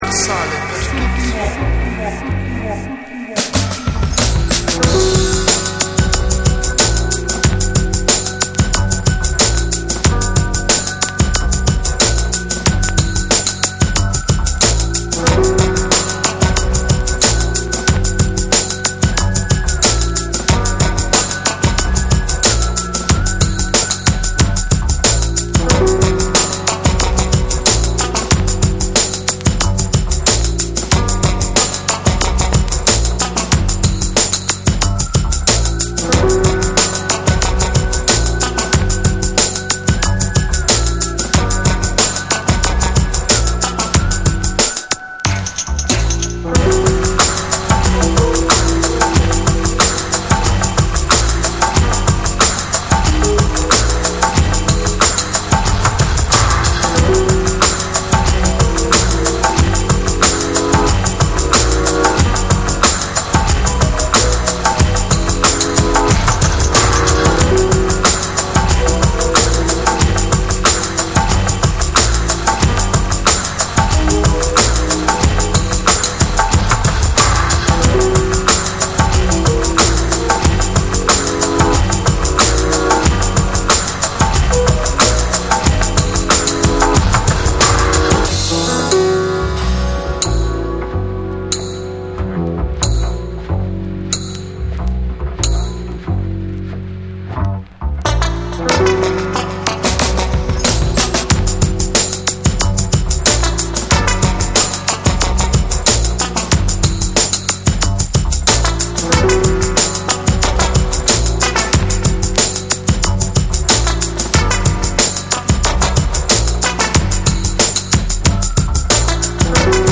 ウッドベースがリードを取りながらスモーキーでジャジーなダウンテンポを展開する(SAMPLE 3)。
BREAKBEATS